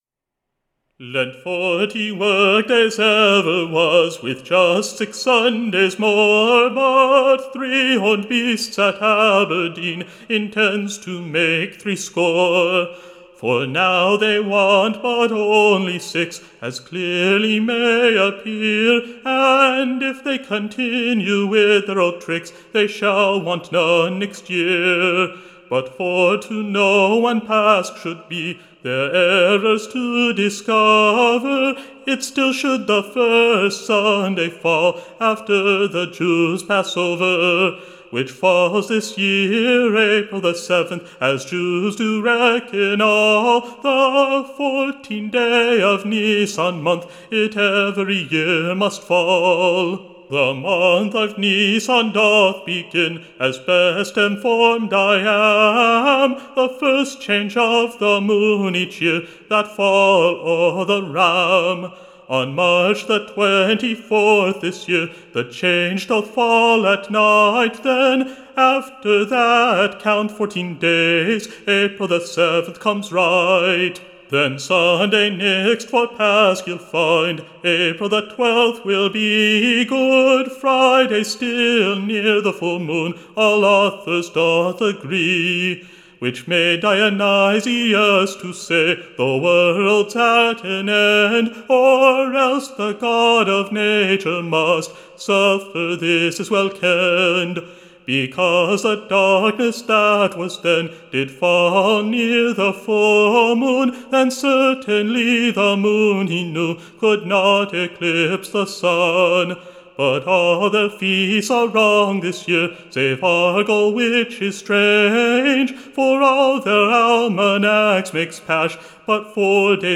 Tune Imprint To the Tune of Robin-hood and the Tanner.